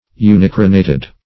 Search Result for " unicarinated" : The Collaborative International Dictionary of English v.0.48: Unicarinated \U`ni*car"i*na`ted\, a. [Uni- + carinated.] Having one ridge or keel.